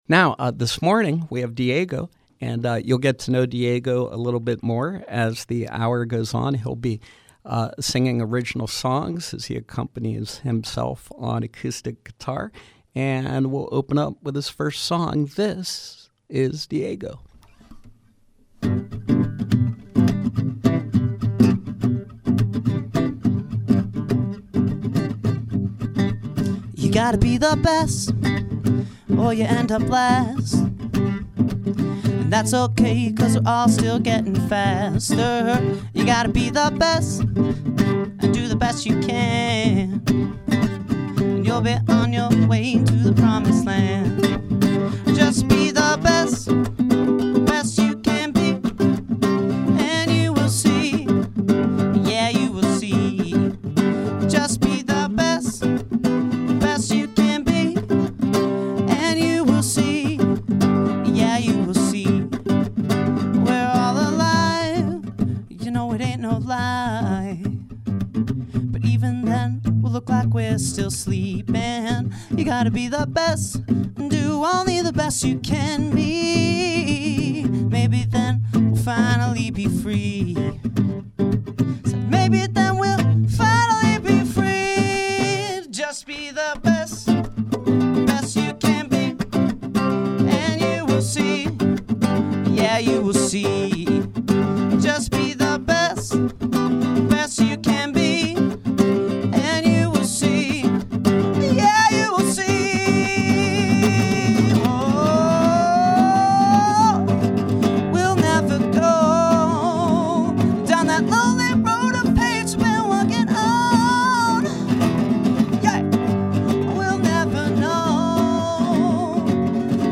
Live music with Pittsburgh-area singer/songwriter